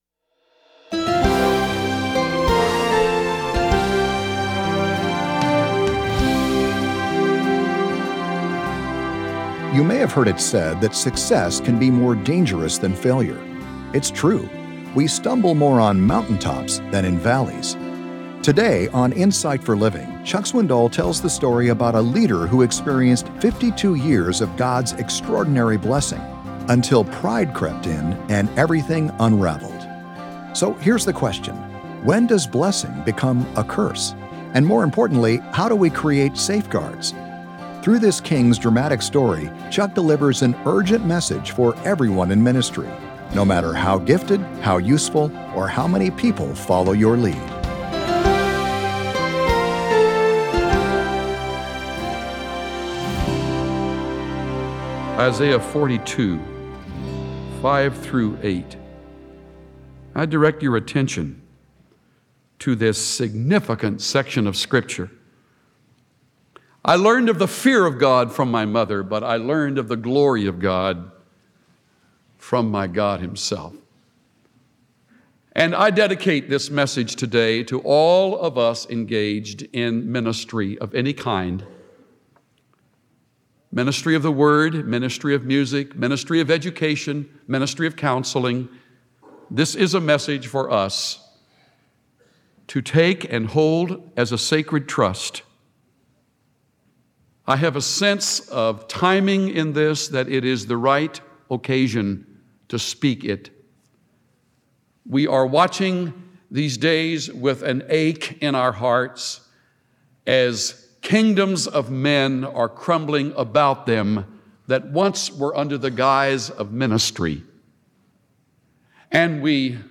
The rise and fall of King Uzziah, recorded in 2 Chronicles 26, is a pertinent example of this tragedy. Tune in to hear Pastor Chuck Swindoll teach on pride, leadership, and God’s glory. Learn the telltale signs of a ministry or a leader hoarding glory for themselves instead of giving it back to God.